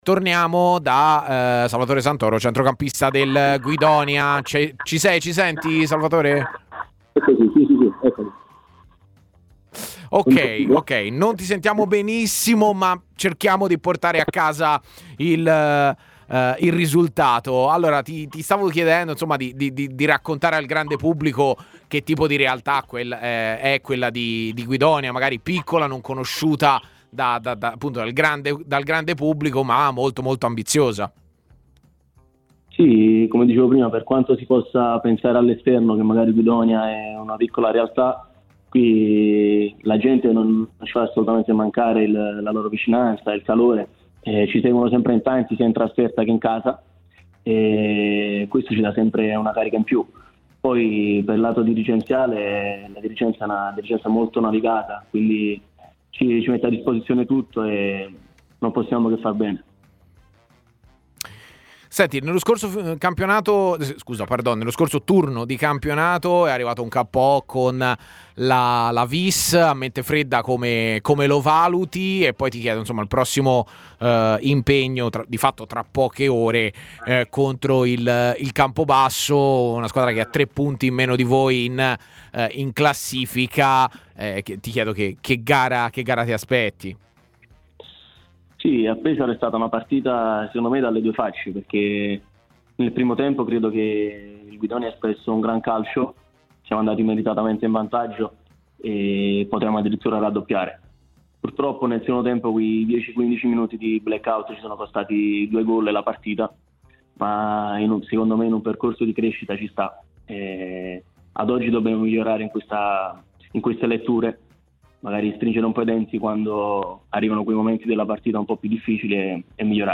è intervenuto nel corso della trasmissione " A Tutta C " per fare il punto della situazione sulla società laziale, a poche ore dal match contro il Campobasso, e sull'intero campionato di Serie C.